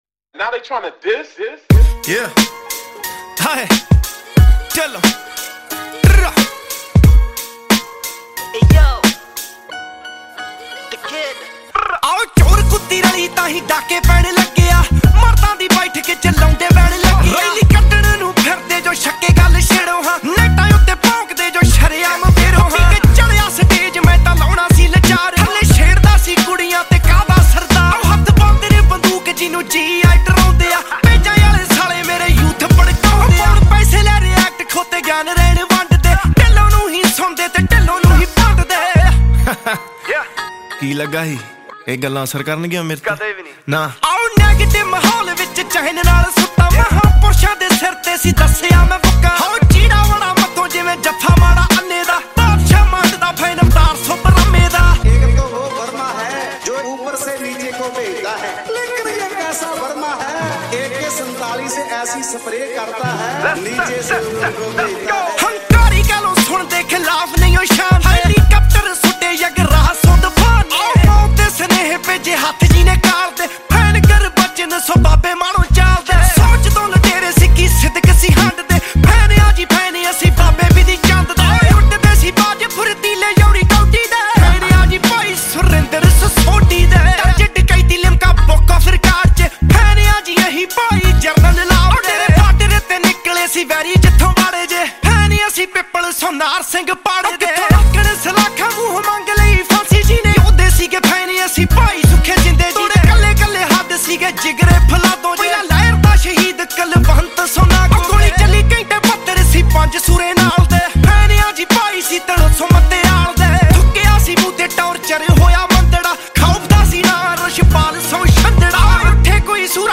Releted Files Of Punjabi Music